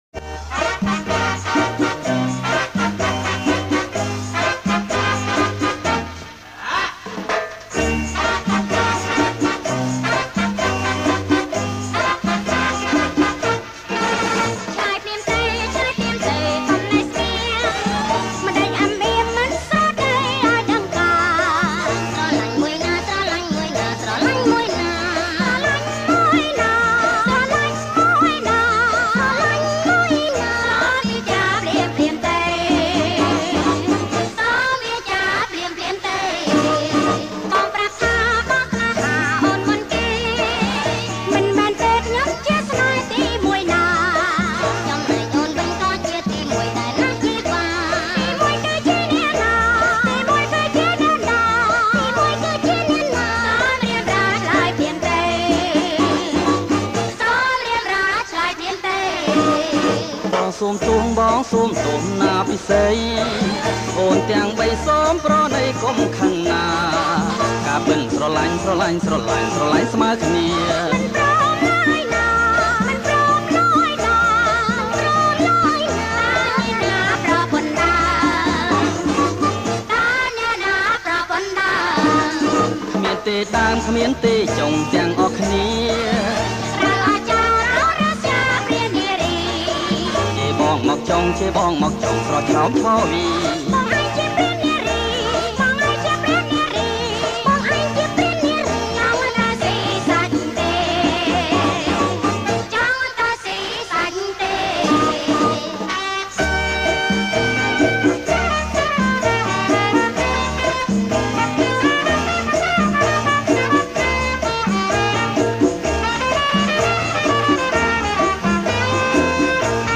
ប្រគំជាចង្វាក់ Cha Cha Cha